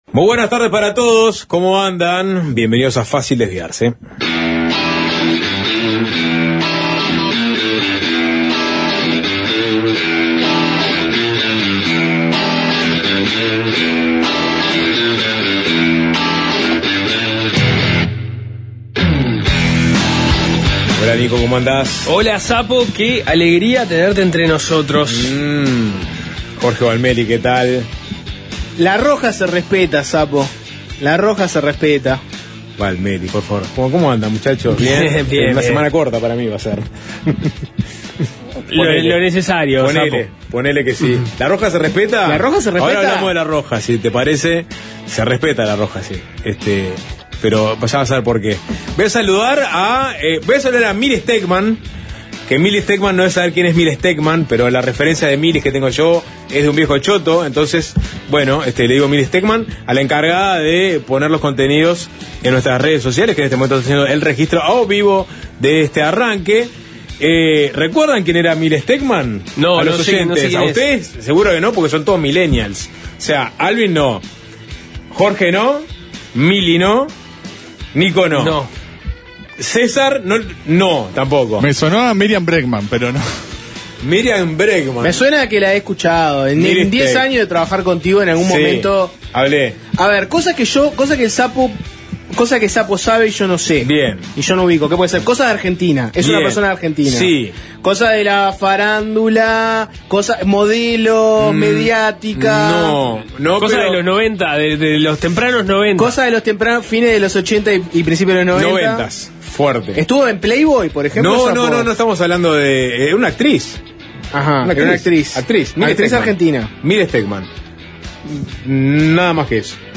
Periodístico con la conducción